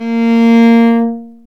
Index of /90_sSampleCDs/Roland L-CD702/VOL-1/STR_Viola Solo/STR_Vla3 _ marc
STR VIOLA 05.wav